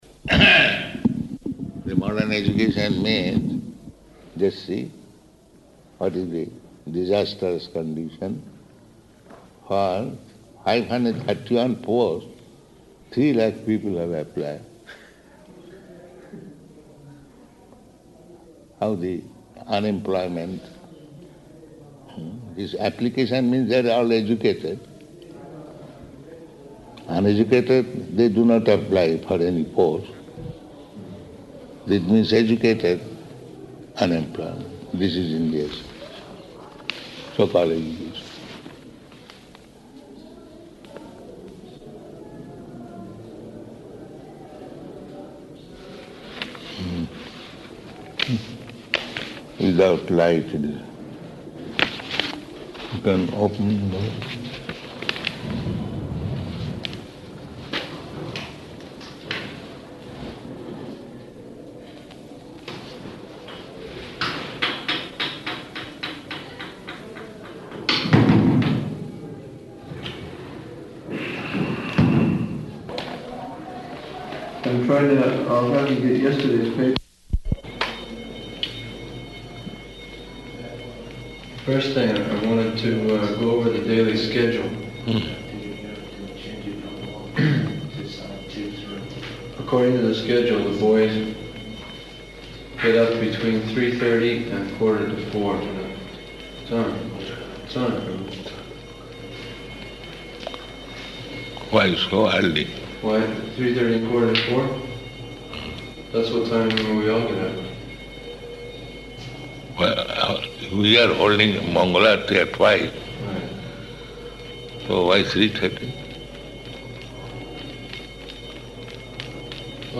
Room Conversation about Gurukula
Room Conversation about Gurukula --:-- --:-- Type: Conversation Dated: November 5th 1976 Location: Vṛndāvana Audio file: 761105R1.VRN.mp3 Prabhupāda: The modern education means...